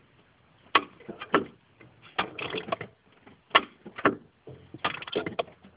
2x Dźwięk zapłonu
Dźwięk zapłonu na prostowniku